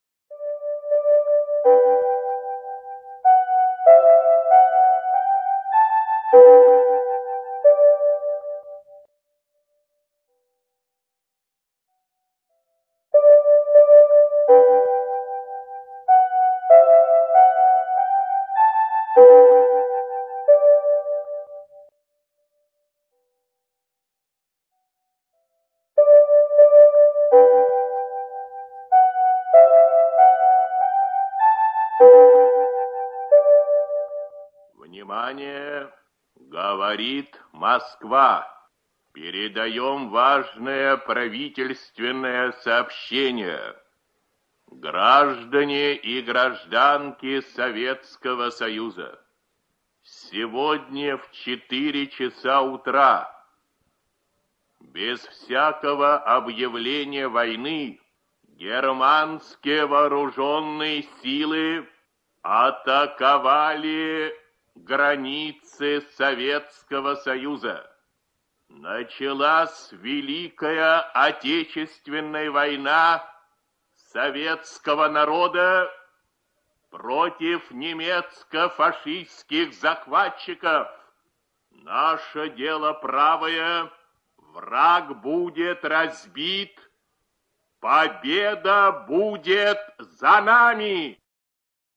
Правительственное сообщение о начале Великой Отечественной войны 22 июня 1941 г. Читает диктор Ю.Б. Левитан. Запись 1941 г.